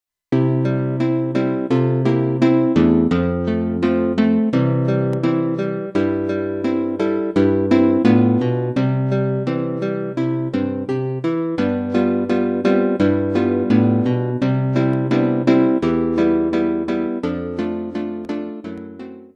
このためにヤマハの「S-YXG50」というソフト音源を使用しています。